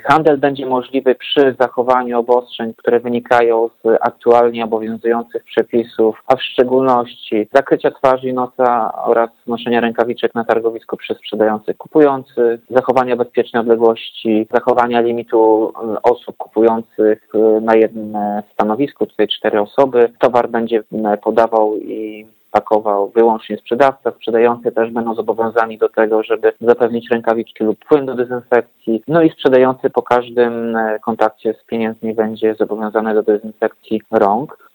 – Pozwalamy na handel, jednak pod pewnymi warunkami – mówi prezydent Ełku Tomasz Andrukiewicz.